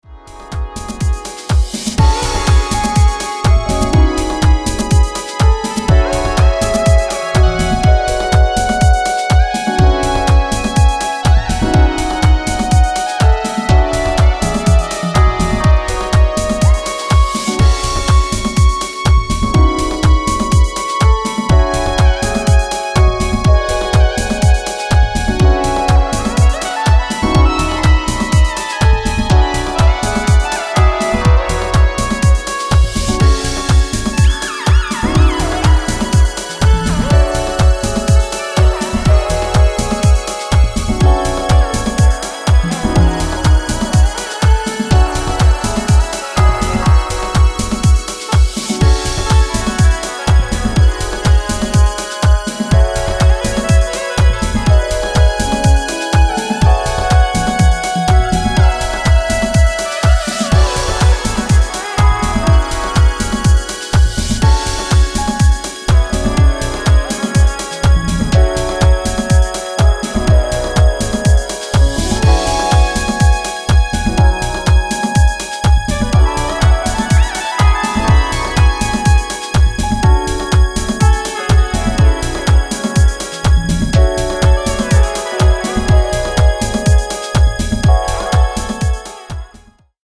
Detroit House